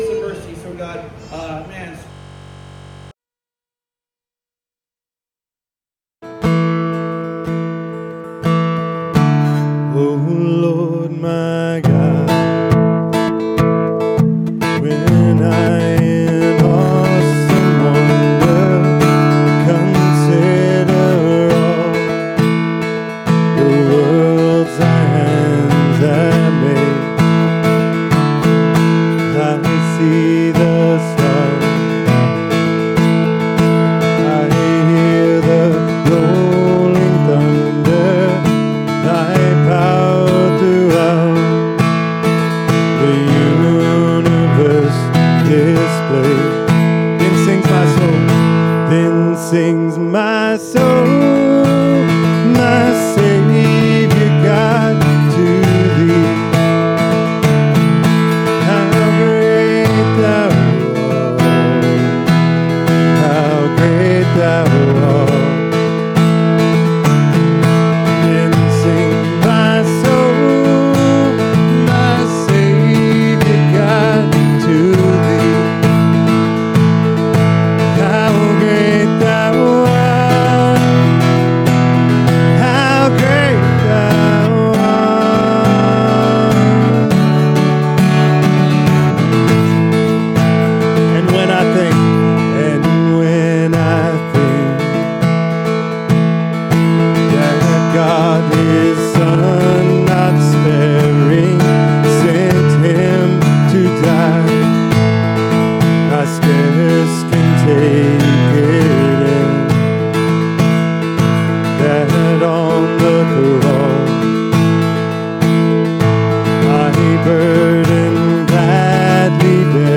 SERMON DESCRIPTION Christians can be rigid on different laws or go to the other extreme of “laws don’t matter” and it is all Christian freedom.